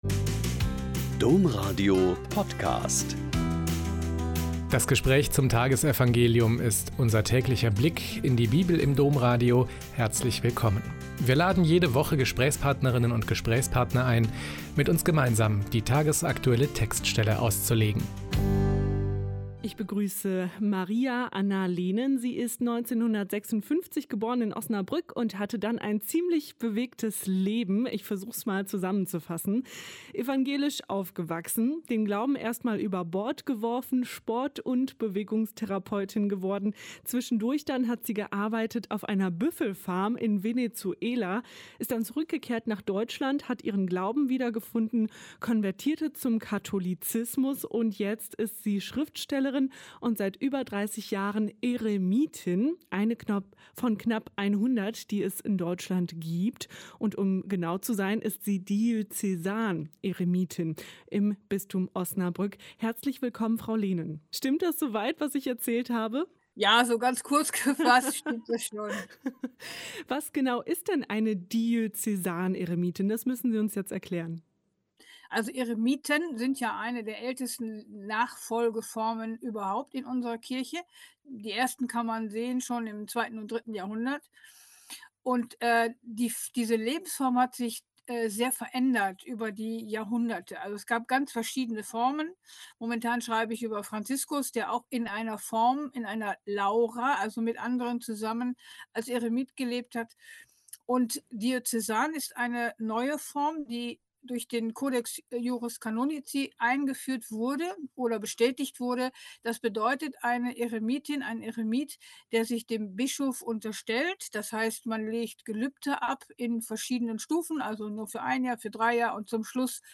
Mt 19,16-22 - Gespräch